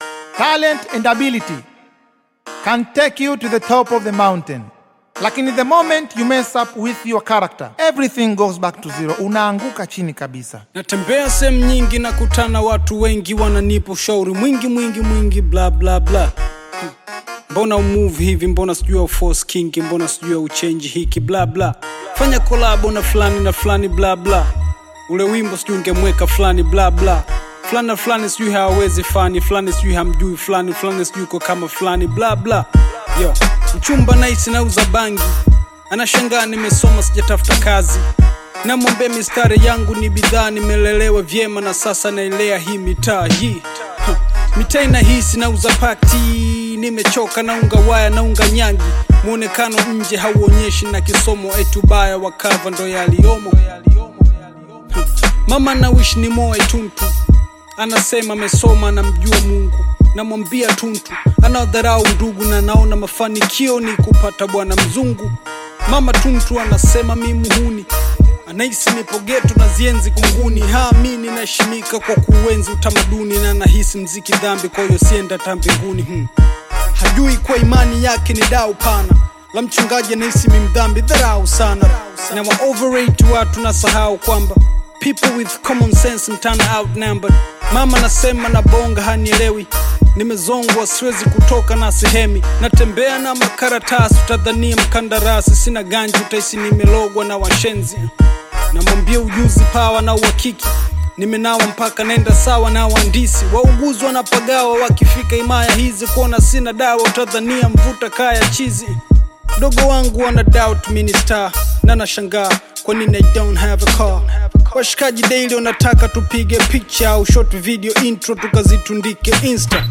Bongo Flava music track
This catchy new song